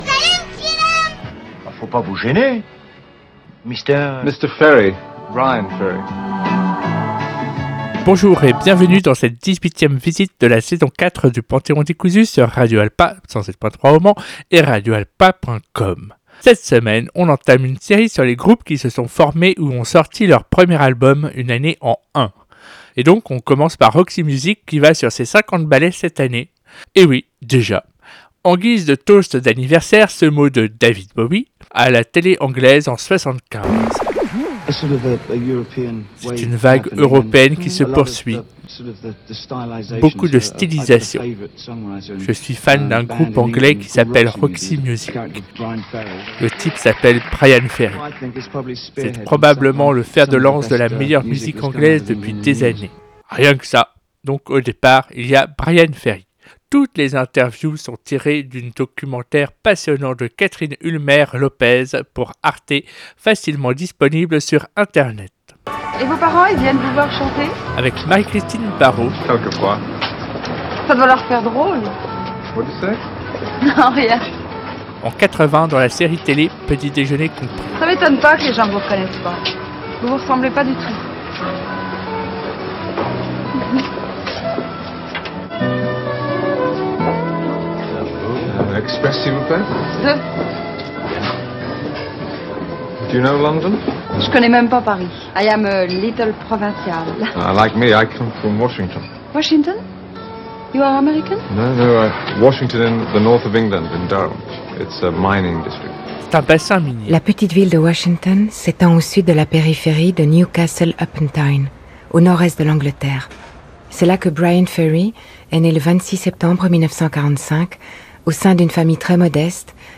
… et comme d’habitude : sons cultes, sons rares et surprises sont au rendez-vous !